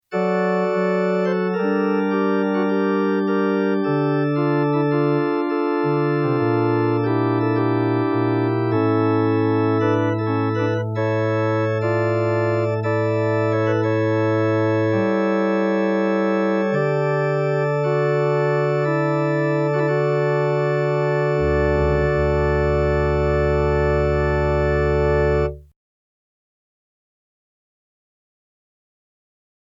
Tous ces sons ont été enregistrées directement en sortie du DX7, donc sans aucun effet : ni reverb, ni chorus.
orgueAC p.171 (« Plein jeu » dans le livre) : orgue d’église avec une registration dite « plein jeu ». Attaque un peu dure…
FM-DX7-Plein-Jeu.mp3